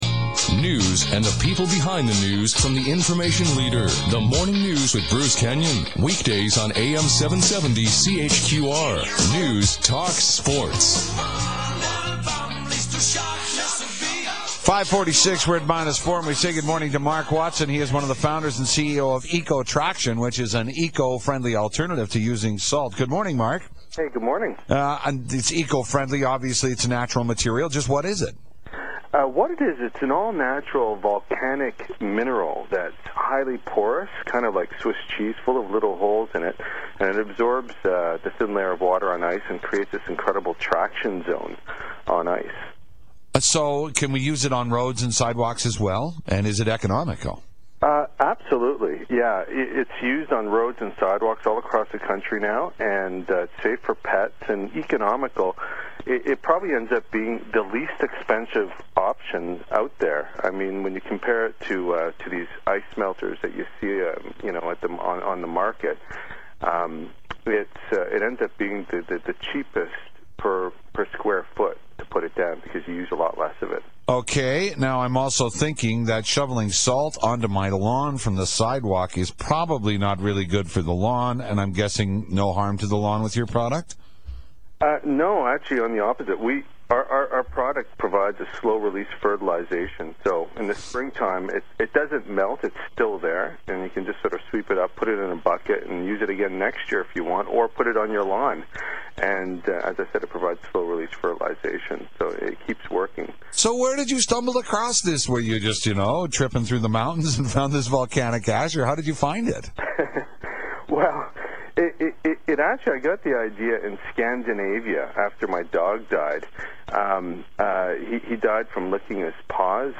February 21, 2008 Calgary radio interview
CalgaryRadio_Feb21_08.mp3